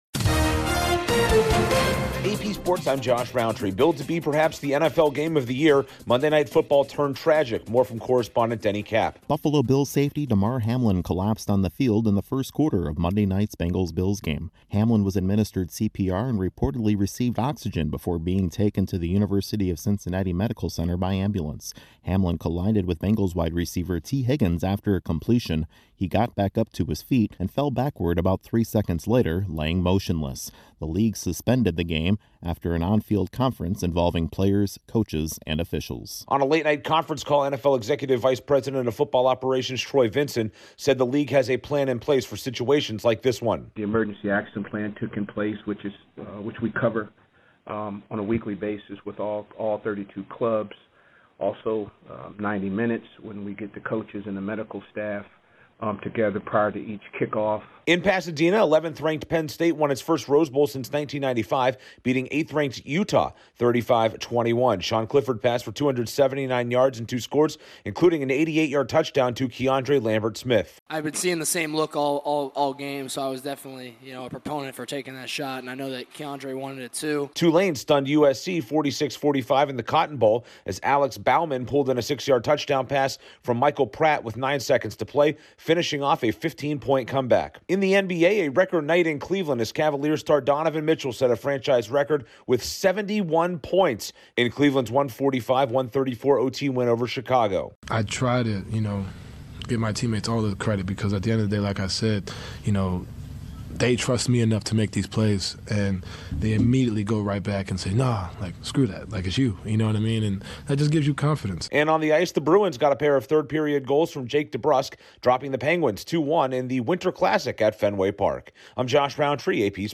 Bills safety Damar Hamlin collapses after a tackle during Monday Night Football, Penn State wins the Rose Bowl, Tulane stuns USC in the Cotton Bowl, Donovan Mitchell scores 71 for the Cavaliers and Boston takes the Winter Classic NHL game at Fenway Park. Correspondent